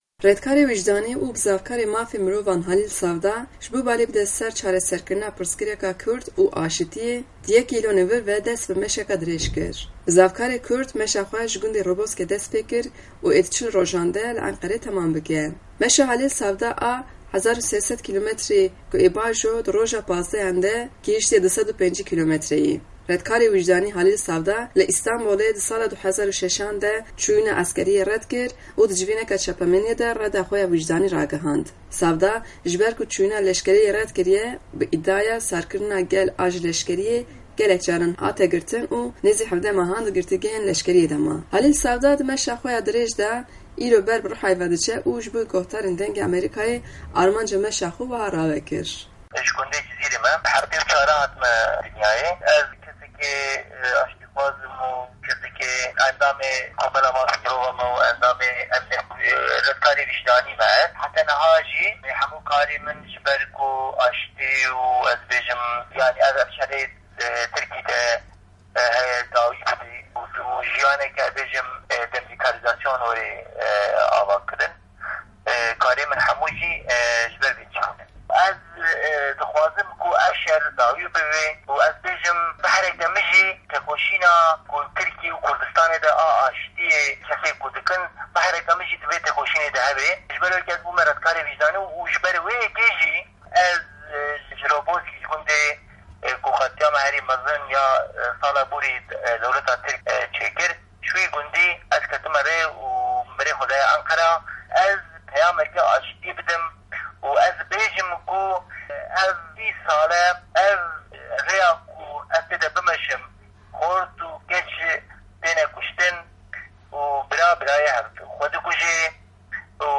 Raport